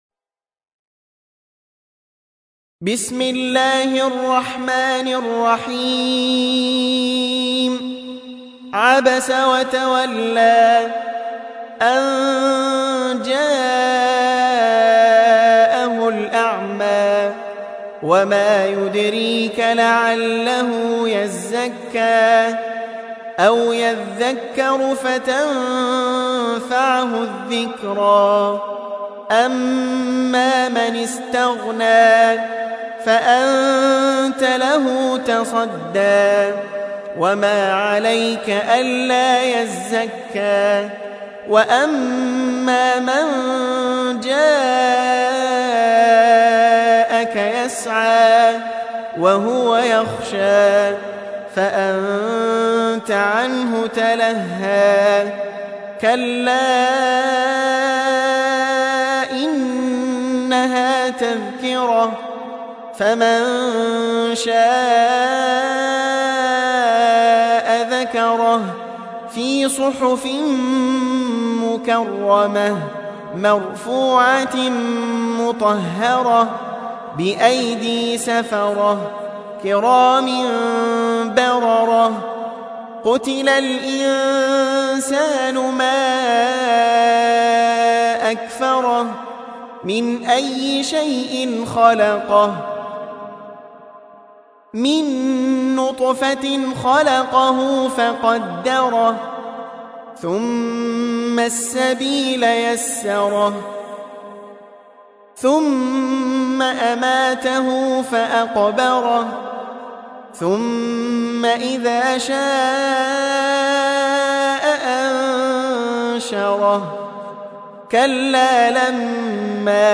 80. Surah 'Abasa سورة عبس Audio Quran Tarteel Recitation
Surah Repeating تكرار السورة Download Surah حمّل السورة Reciting Murattalah Audio for 80.